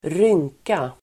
Uttal: [²r'yng:ka]